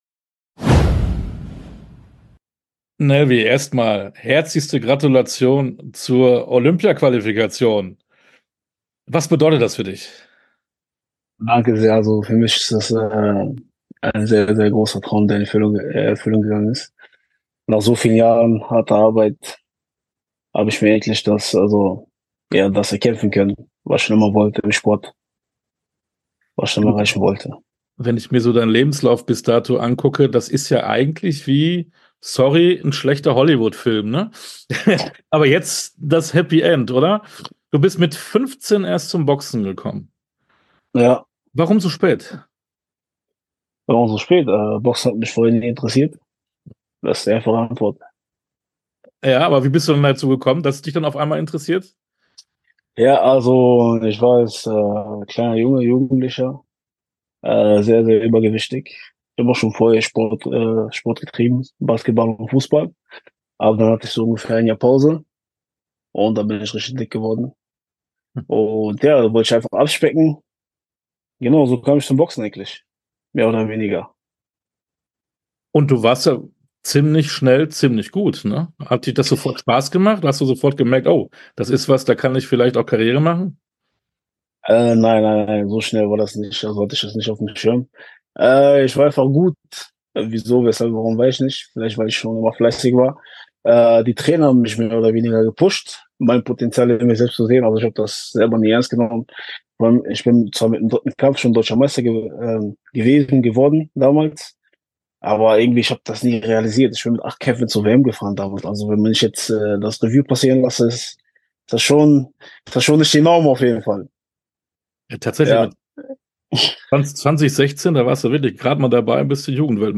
komplett Interview